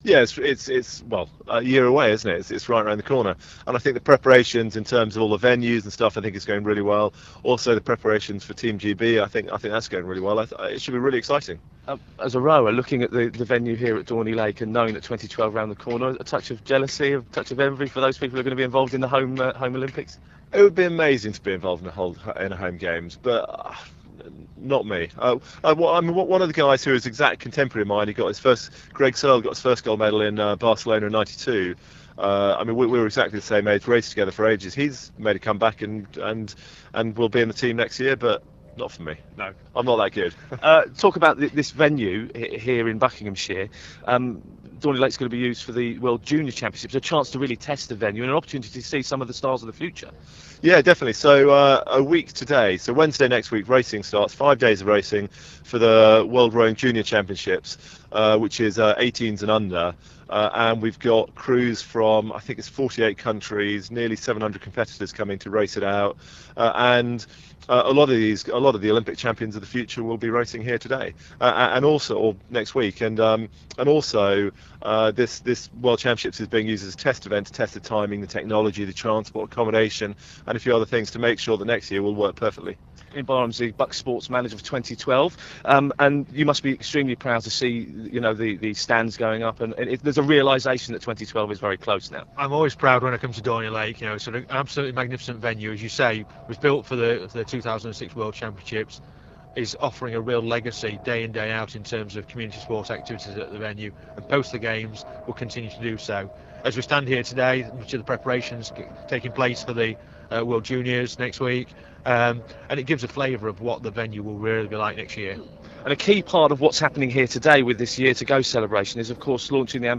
spoke to Ben Hunt-Davis, who won Gold at the Sydney Olympics in the Rowing.